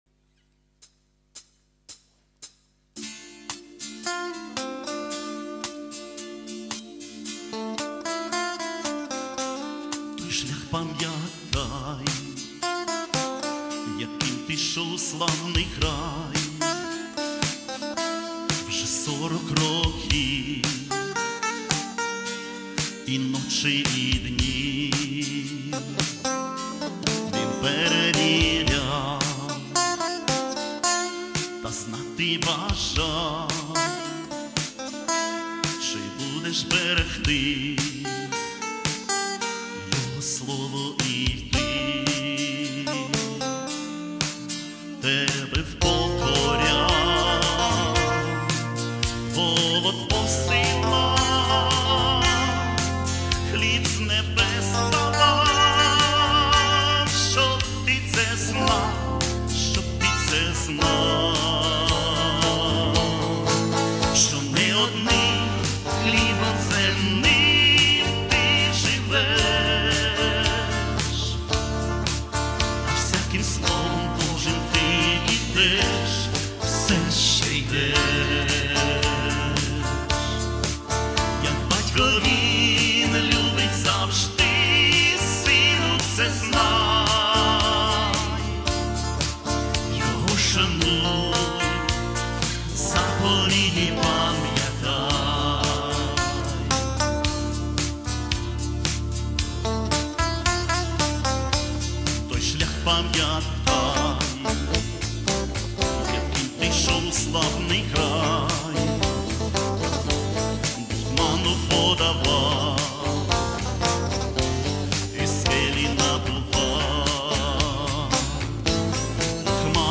49 просмотров 258 прослушиваний 2 скачивания BPM: 100